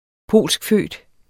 Udtale [ -ˌføˀd ]